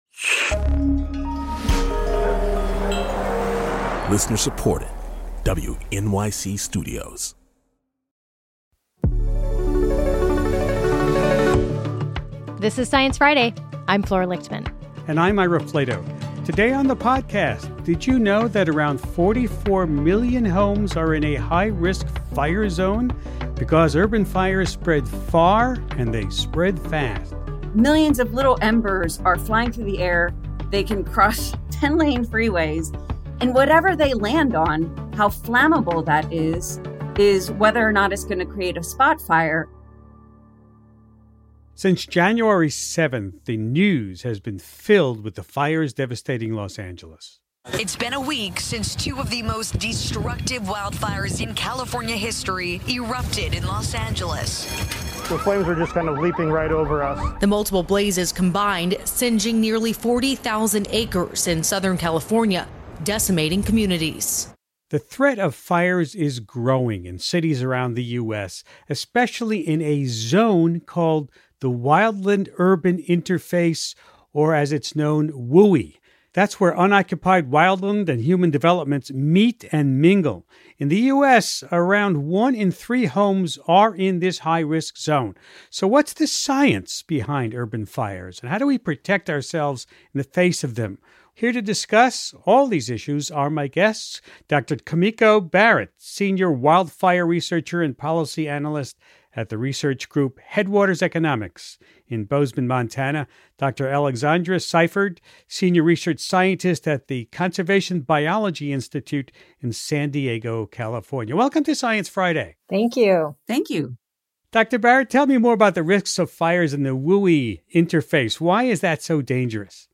Ira Flatow talks with